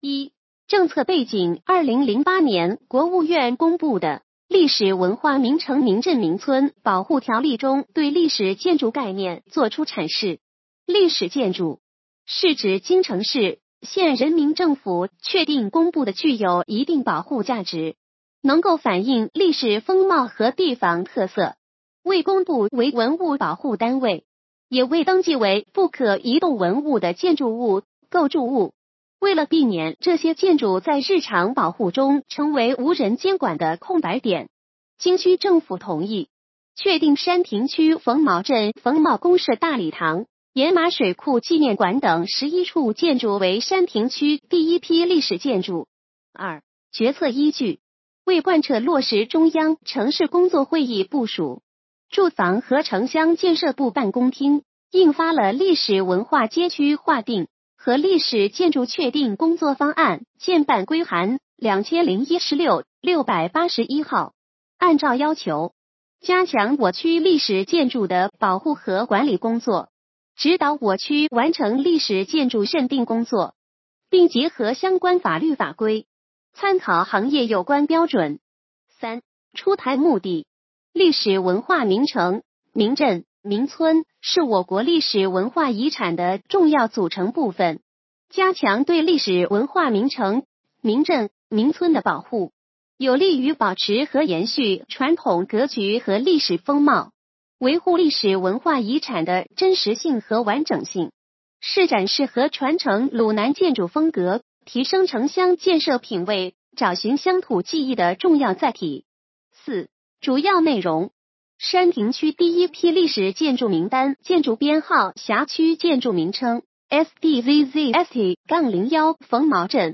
语音解读：山亭区人民政府办公室关于公布山亭区第一批历史建筑的通知